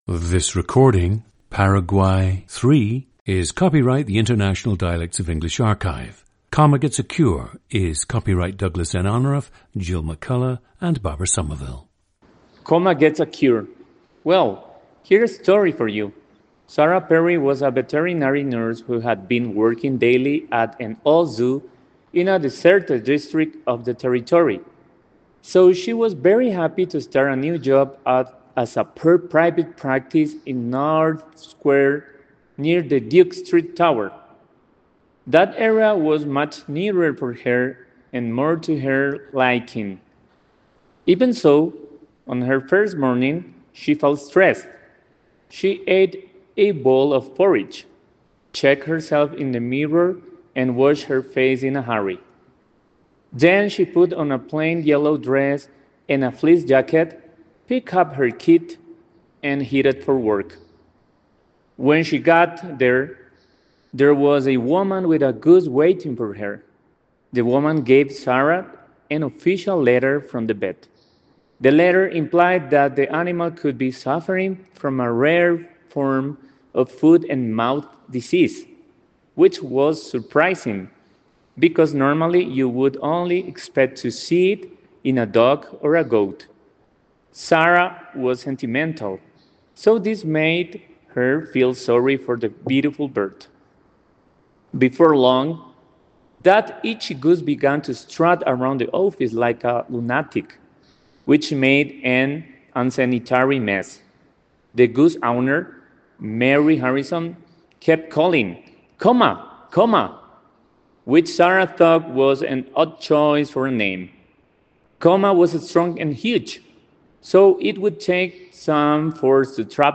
Listen to Paraguay 3, a 40-year-old man from Ciudad del Este, Paraguay.
GENDER: male
The subject speaks Guaraní, Portuguese, Spanish, and English.
The recordings average four minutes in length and feature both the reading of one of two standard passages, and some unscripted speech.